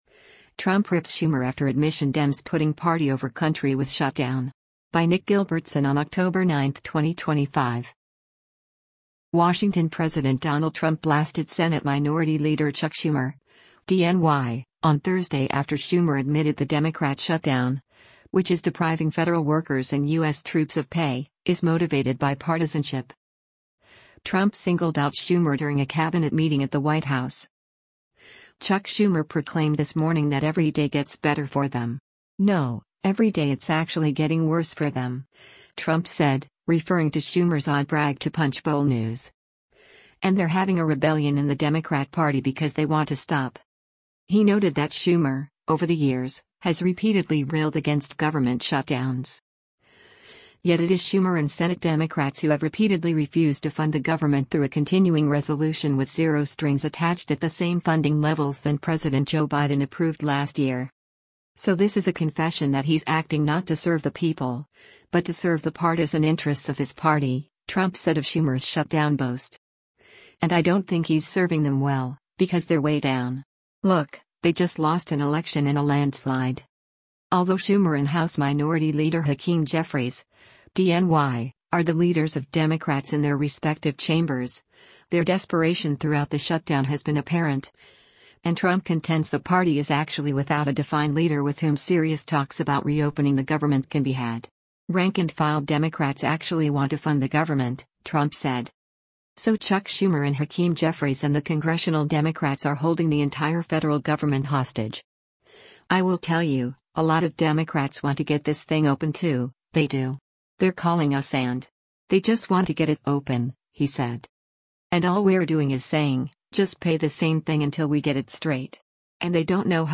Trump singled out Schumer during a Cabinet meeting at the White House.